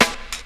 • Snare Single Hit E Key 115.wav
Royality free snare drum sound tuned to the E note. Loudest frequency: 2507Hz
snare-single-hit-e-key-115-FLC.wav